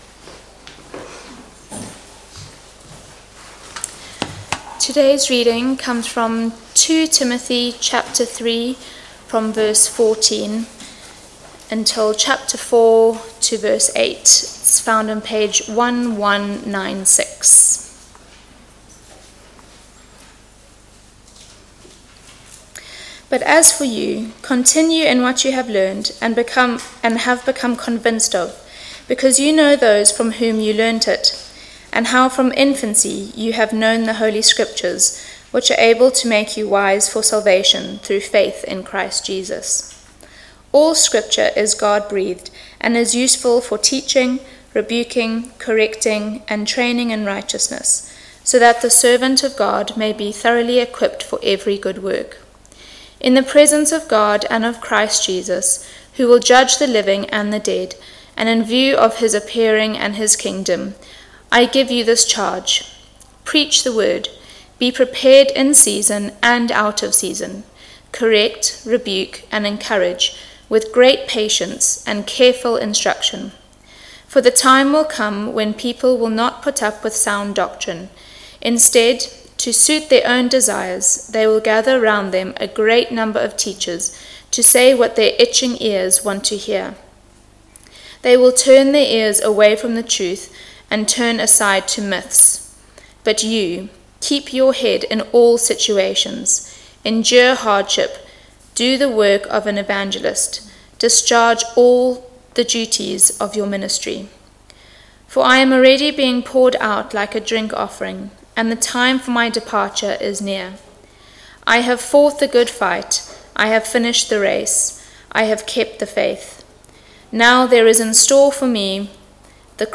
Passage: 2 Timothy 3:14-4:8 Service Type: Weekly Service at 4pm